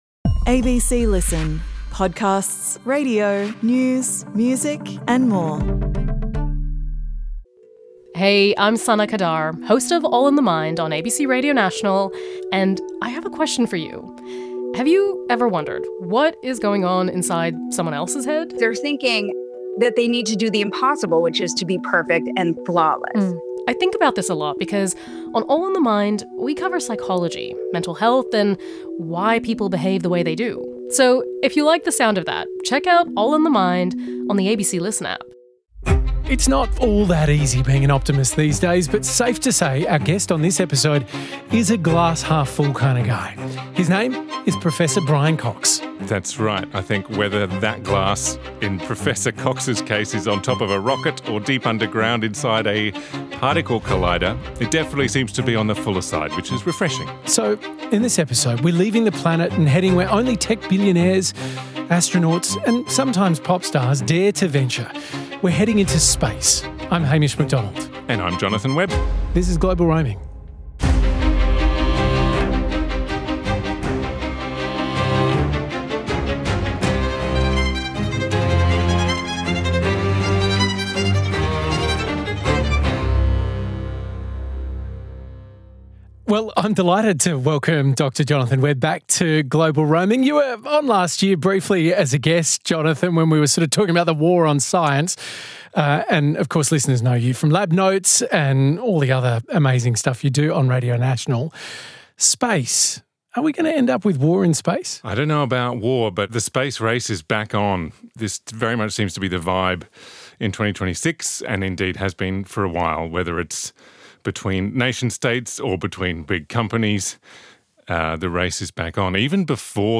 Guest: Professor Brian Cox, UN Champion for Space Get in touch: We'd love to hear from you!